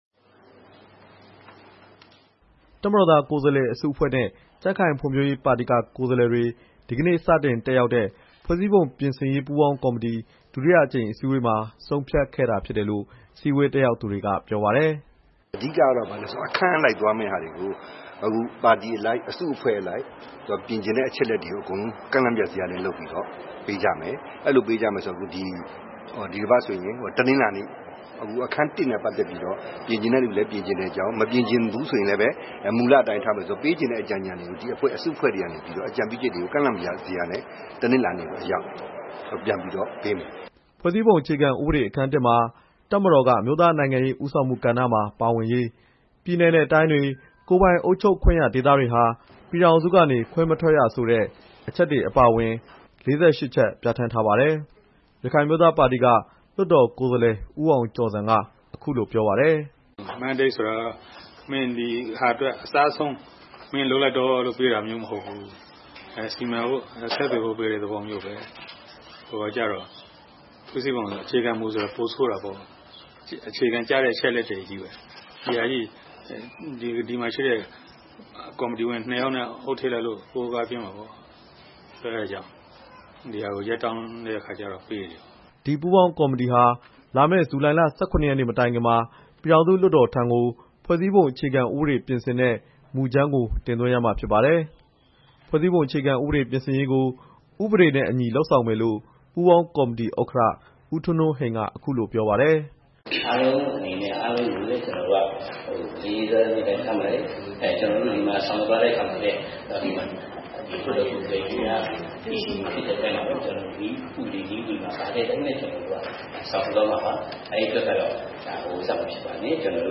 နေပြည်တော်က ပြည်ထောင်စုလွှတ်တော် အဆောက်အအုံမှာ ကျင်းပတဲ့ ပူးပေါင်းကော်မတီ ဒုတိယအကြိမ် အစည်းအဝေး အဖွင့်မိန့်ခွန်းမှာ ပြောခဲ့တာပါ။
ဒါကတော့ အင်န်အယ်လ်ဒီပါတီက ကိုယ်စားလှယ် ဦးအောင်ကြည်ညွန့် ပြောသွားတာပါ။
ရခိုင်အမျိုးသားပါတီက လွှတ်တော်ကိုယ်စားလှယ် ဦးအောင်ကျော်ဇံ က အခုလို ပြောပါတယ်။
ဖွဲ့စည်းပုံအခြေခံဥပဒေပြင်ဆင်ရေးကို ဥပဒေနဲ့အညီ လုပ်ဆောင်မယ်လို့ ပူးပေါင်းကော်မတီ ဥက္ကဋ္ဌ ဦးထွန်းထွန်းဟိန်က အခုလိုပြောပါတယ်။